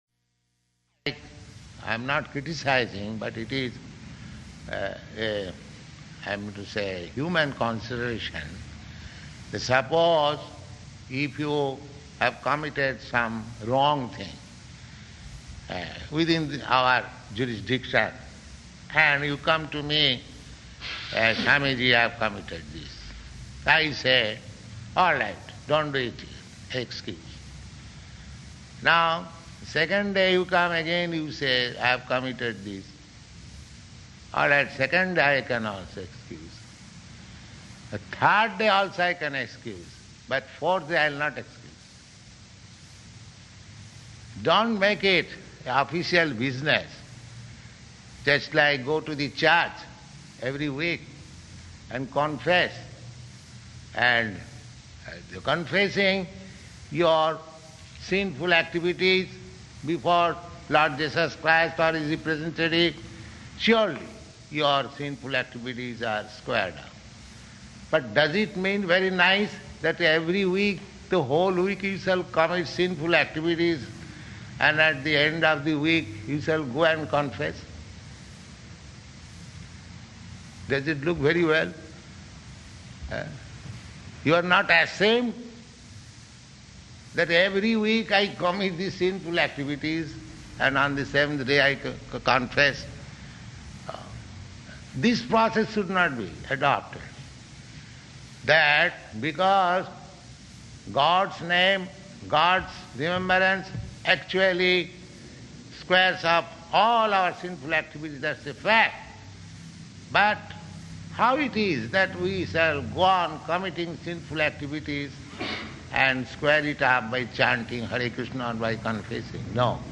Initiation Lecture
Initiation Lecture --:-- --:-- Type: Initiation Dated: December 19th 1968 Location: Los Angeles Audio file: 681219IN-LOS_ANGELES.mp3 Prabhupāda: I am not criticizing, but it is, I mean to say, a human consideration.